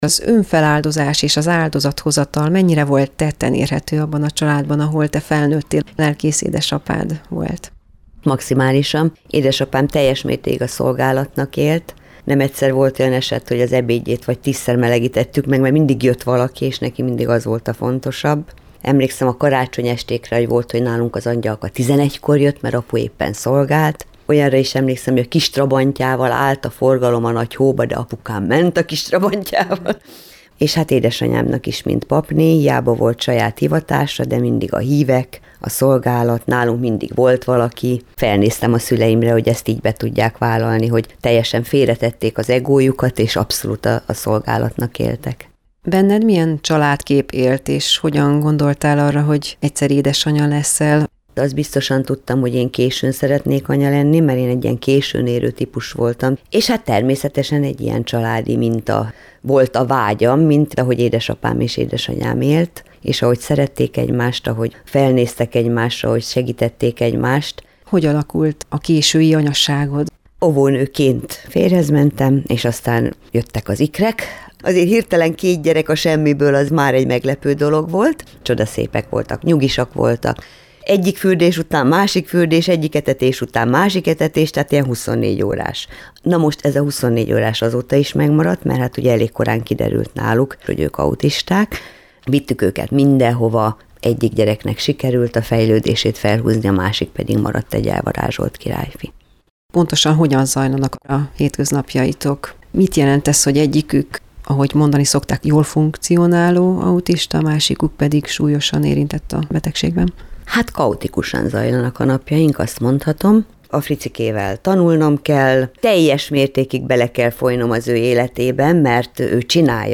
Mindennapjaihoz kreativitást, erőt, derűt Istenben való bizalma, hite ad számára. A Lélekhangoló műsorában elhangzott interjú részlete szól